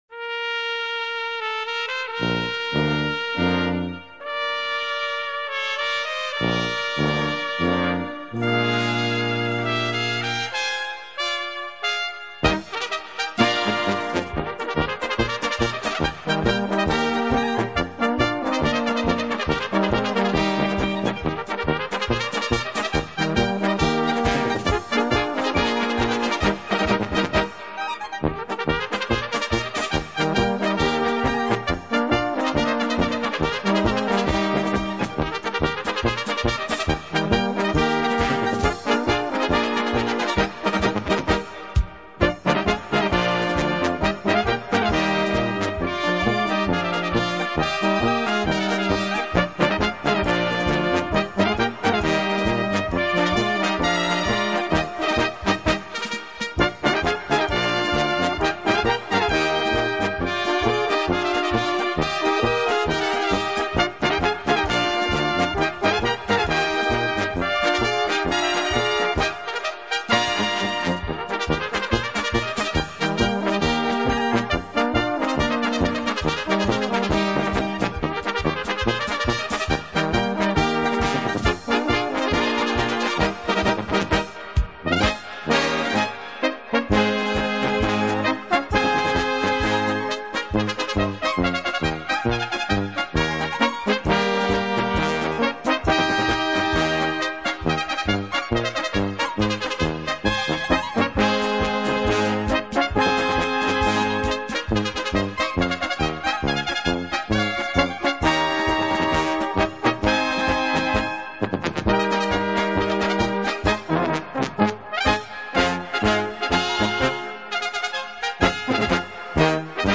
Gattung: Solostück für 2 Trompeten und Blasorchester
Besetzung: Blasorchester
Für zwei Solo-Trompeten und Blasorchester.